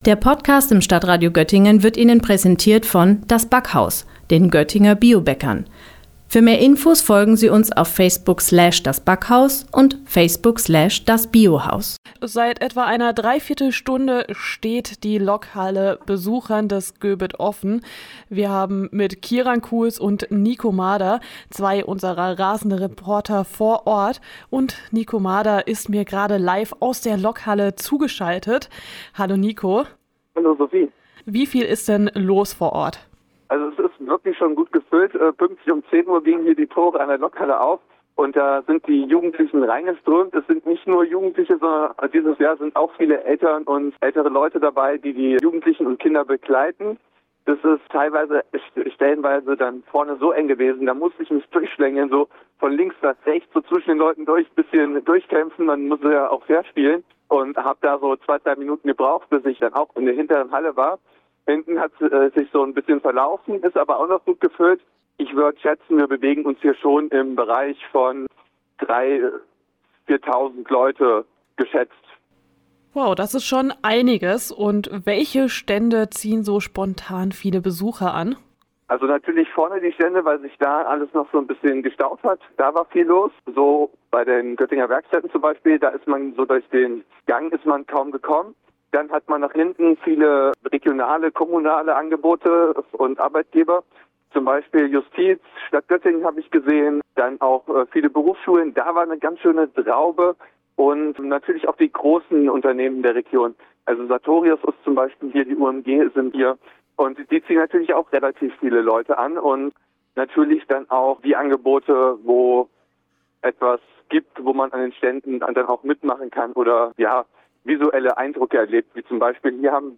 GöBit 2026: Liveschalte in die Lokhalle